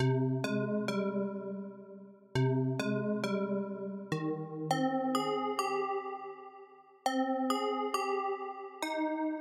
合成钟
描述：弹跳的合成器铃声
Tag: 102 bpm Hip Hop Loops Bells Loops 1.58 MB wav Key : Unknown FL Studio